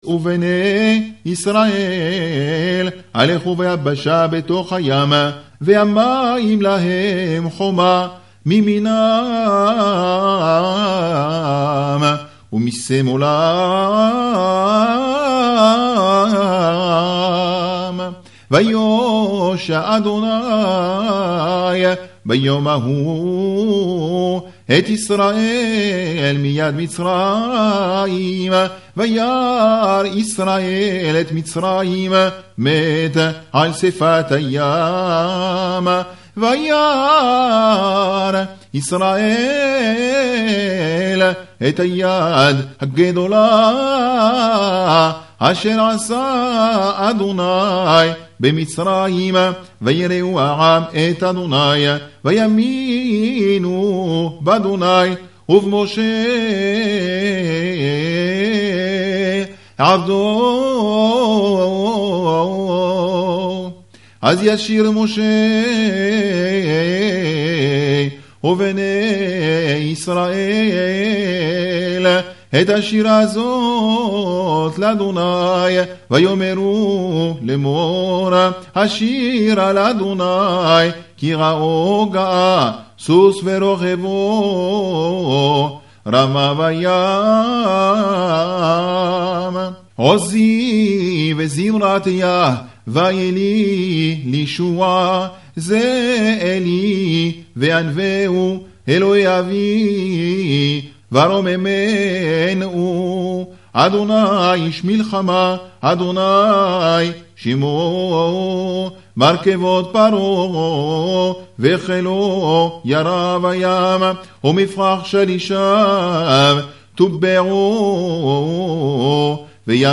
קריאת "שירת הים" מתוך פרשת בשלח בטעם עליון